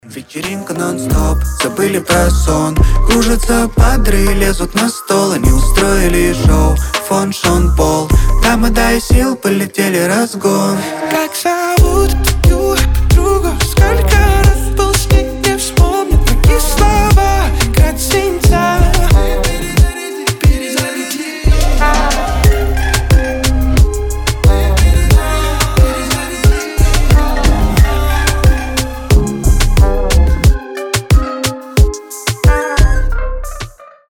• Качество: 320, Stereo
Хип-хоп
заводные
dancehall
дуэт